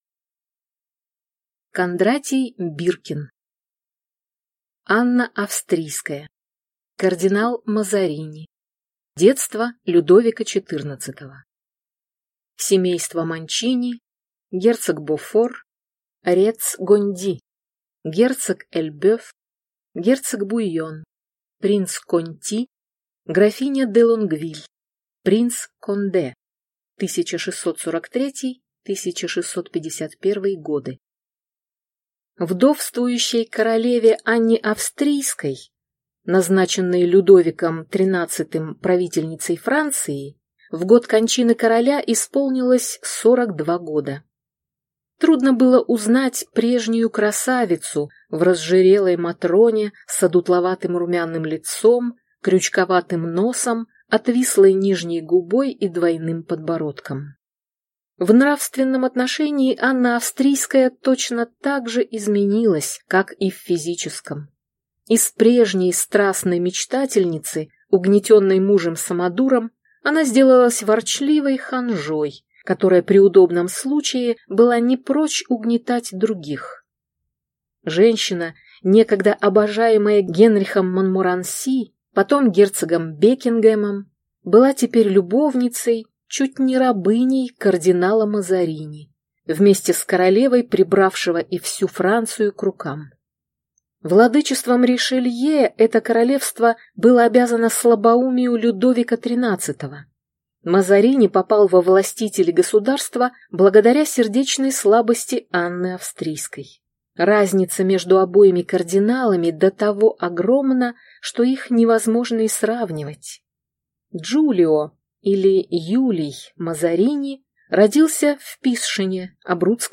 Аудиокнига Анна Австрийская. Кардинал Мазарини. Детство Людовика XIV | Библиотека аудиокниг